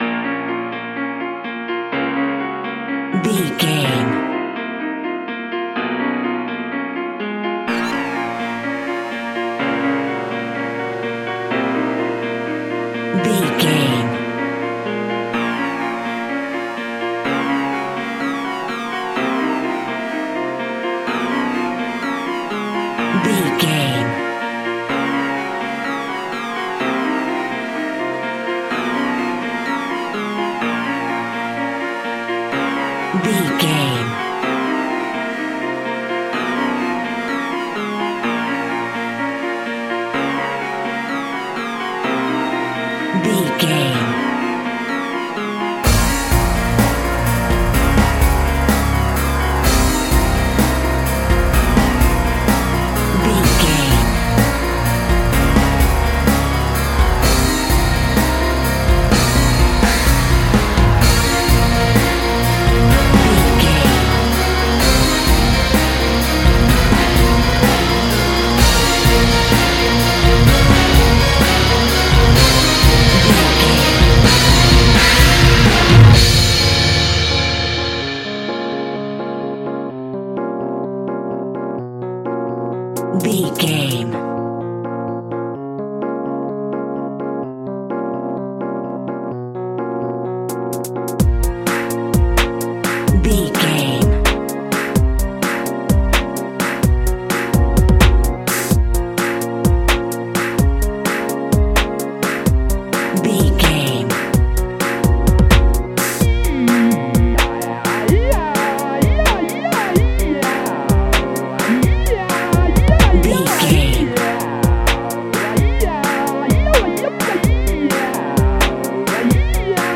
Chilling Breakbeat Horror.
Aeolian/Minor
ominous
dark
eerie
synthesizer
ambience
pads
eletronic